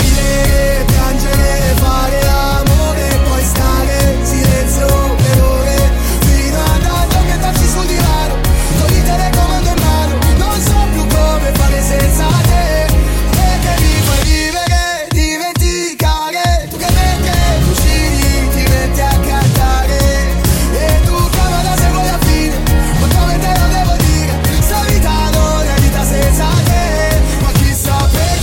Genere: italiana,sanremo2025,pop.ballads,rap,hit